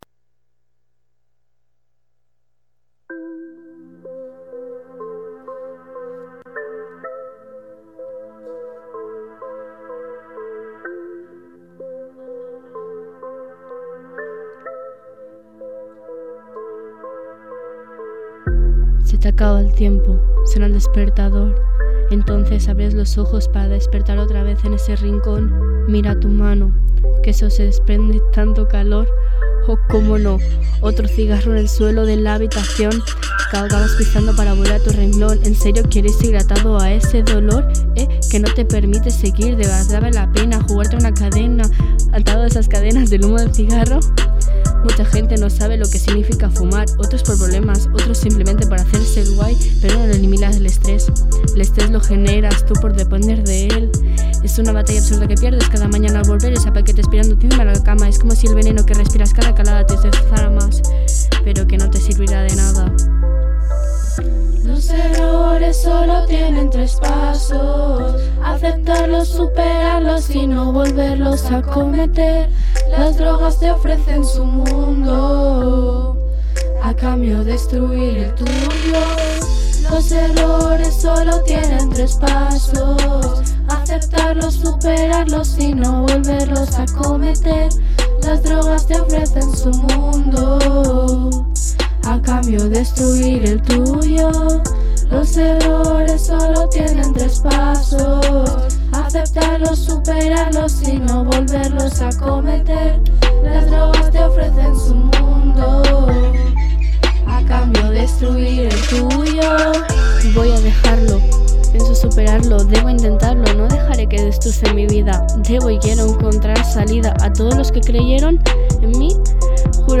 • Estrena del TRAP del tabac. Un grup del Casal de Joves ha fet un taller de cant durant tres mesos i han elaborat un Trap en el que conviden a reflexionar els altres joves sobre el consum de tabac, alcohol i drogues en general.